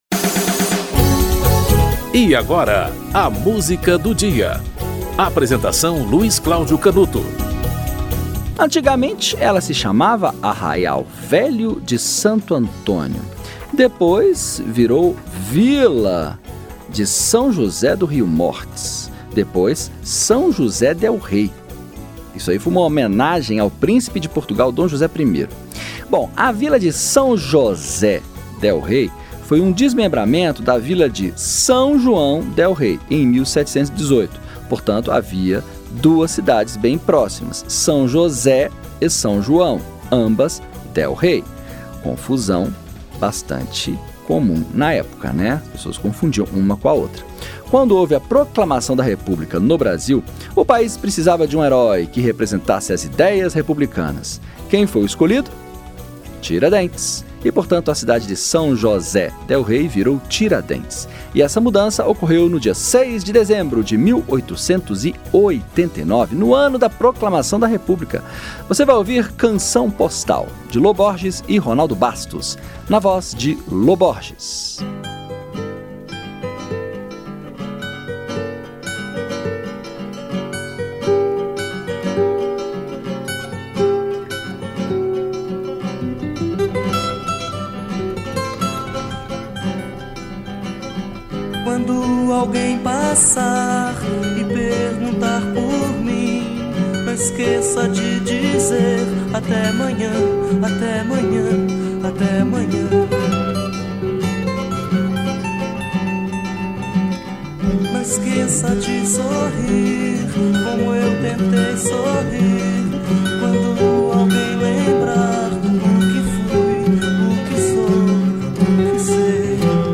Lô Borges - Canção Postal (Lô Borges e Ronaldo Bastos)
O programa apresenta, diariamente, uma música para "ilustrar" um fato histórico ou curioso que ocorreu naquele dia ao longo da História.